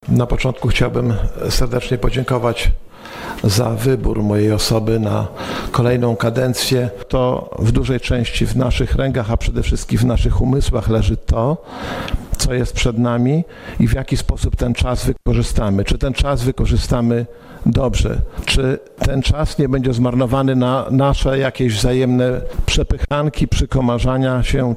Powiat łomżyński: Pierwsza sesja Rady Powiatu VII Kadencji
Większością głosów radnych funkcję Starosty Łomżyńskiego nadal będzie pełnił Lech Marek Szabłowski: